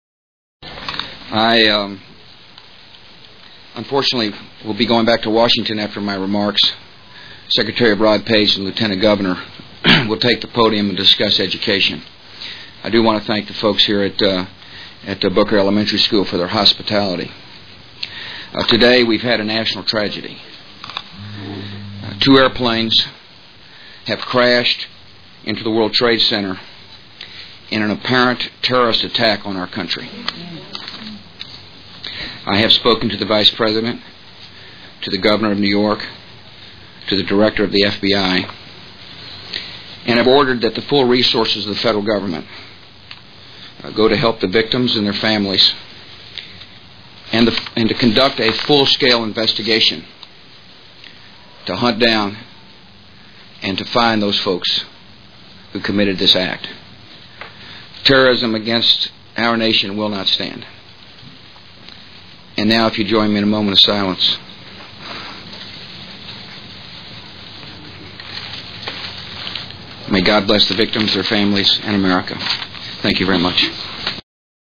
President Bush's First Comments On The Attack On America (186 KB)